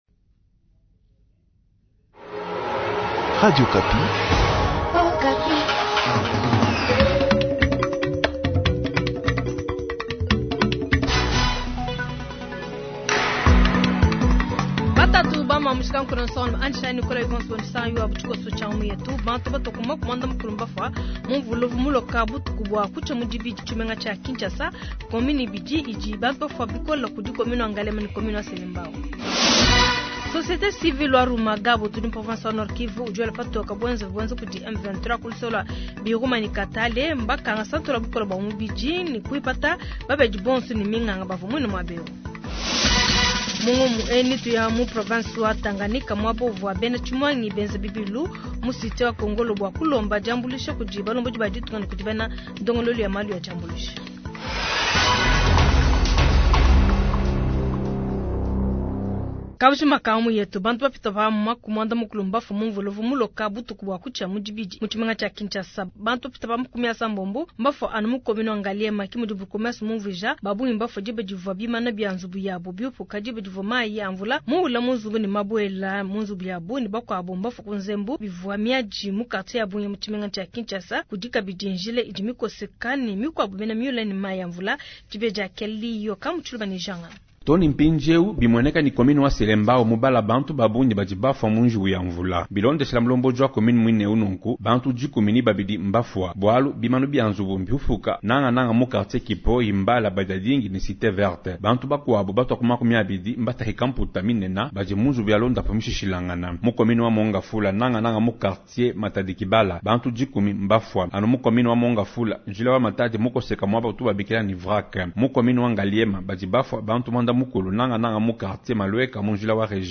Journal Matin
Kin: témoignage du bourmestre de Selembao